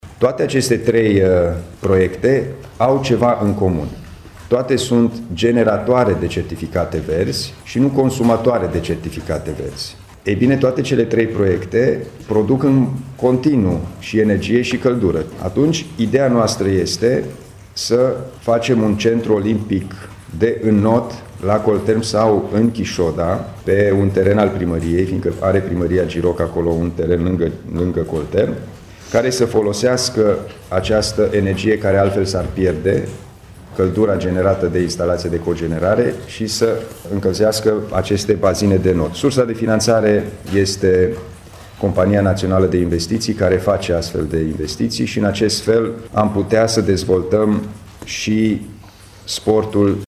Deja Consiliul Județean Timiș a purtat discuții cu potențialul investitor austriac, spune președintele instituției, Alin Nica.